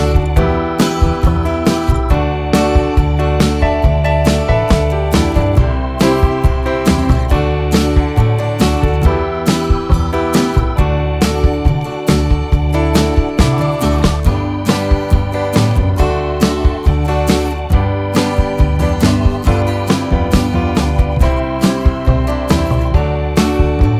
Down 3 Semitones Pop (1970s) 4:03 Buy £1.50